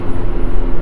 lwmovefloor.ogg